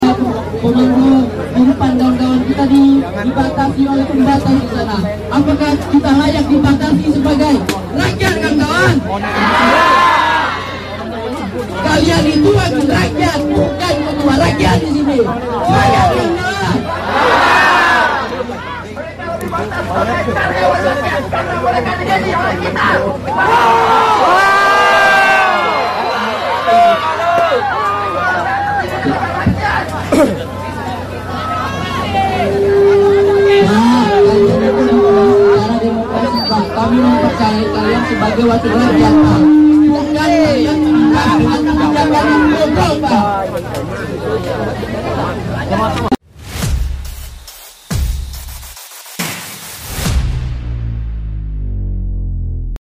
*Suasana demo mahasiswa di depan sound effects free download
*Suasana demo mahasiswa di depan gedung DPRD Bangka Belitung* Mahasiswa di Pulau Bangka melakukan aksi unjuk rasa di depan gedung DPRD Provinsi Kepulauan Bangka Belitung pada Senin (1/9/2025) untuk menyuarakan aspirasinya terkait tugas dan fungsi anggota dewan.